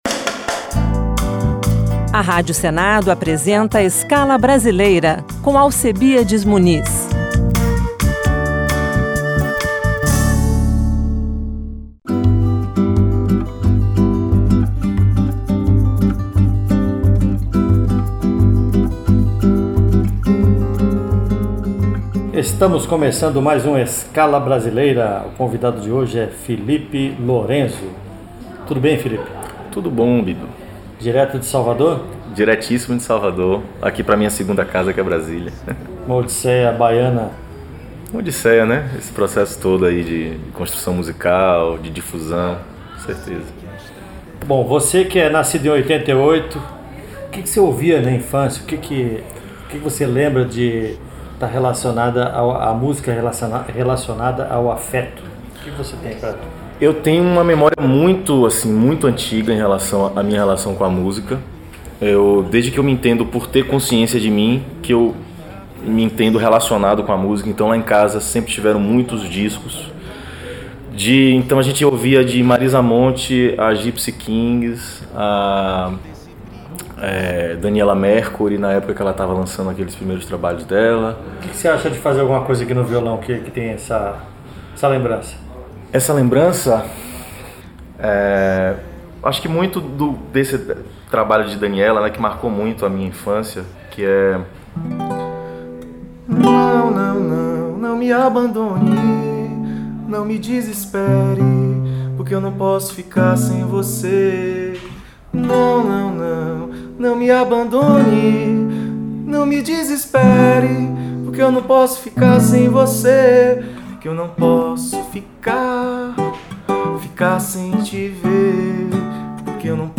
no estúdio na rádio